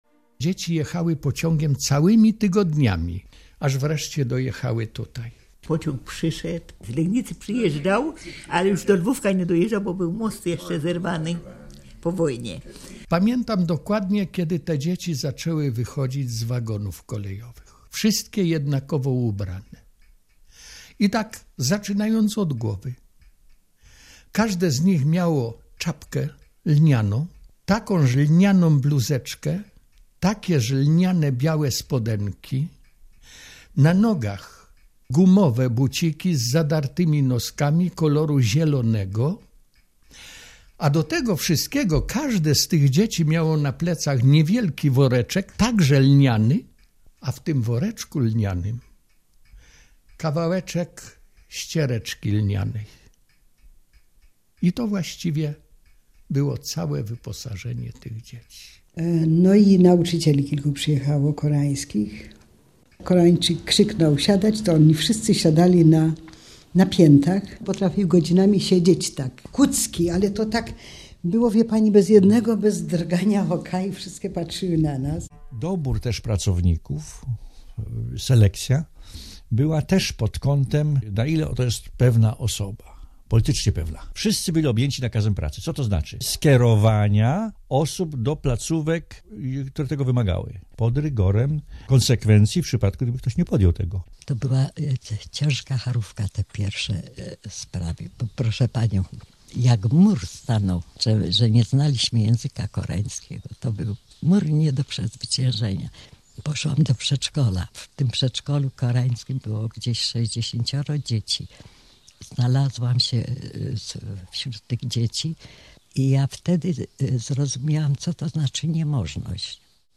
+ Reportaże radiowe:
fragment reportażu (3,92 Mb, 4 min.)